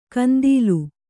♪ kandīlu